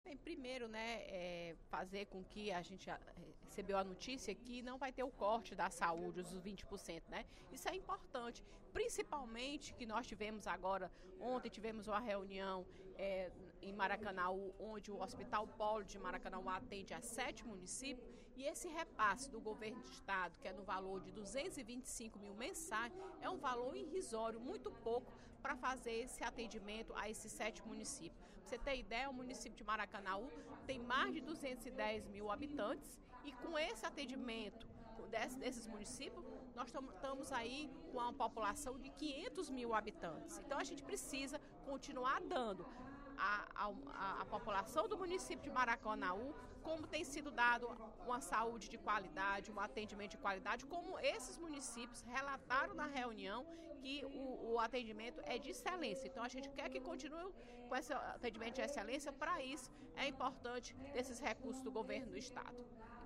A deputada Fernanda Pessoa (PR) avaliou, durante o primeiro expediente da sessão plenária desta quinta feira (20/05), como positiva a decisão de suspender os cortes de 20% na área da saúde.